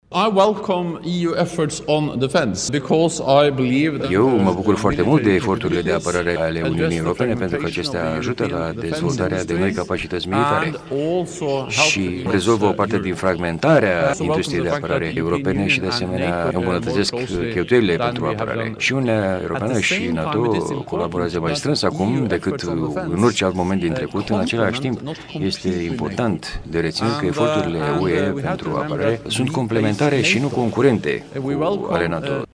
După discuţii, Jens Stoltenberg a declarat că eforturile Uniunii Europene pentru apărare sunt complementare şi nu concurente cu ale NATO, subliniind că Alianţa rămâne piatra de temelie a securităţii europene:
Jens-Stoltenberg.mp3